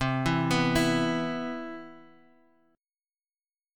E5/C chord